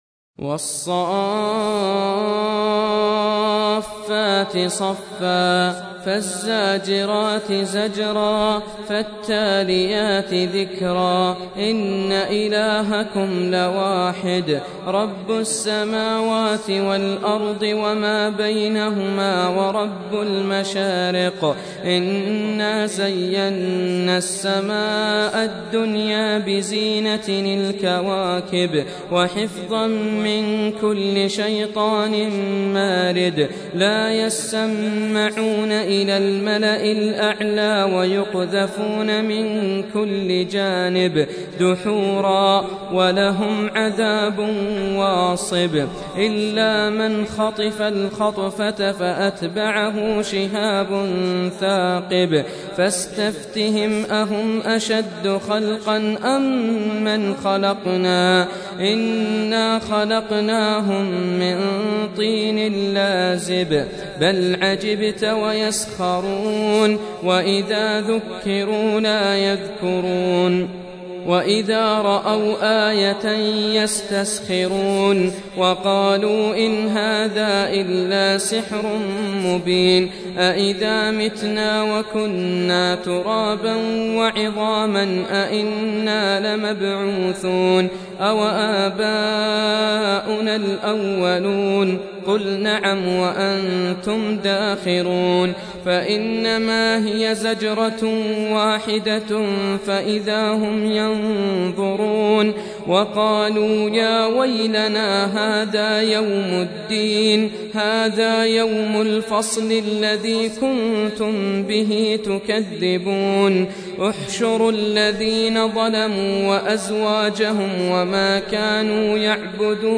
Surah Repeating تكرار السورة Download Surah حمّل السورة Reciting Murattalah Audio for 37. Surah As-S�ff�t سورة الصافات N.B *Surah Includes Al-Basmalah Reciters Sequents تتابع التلاوات Reciters Repeats تكرار التلاوات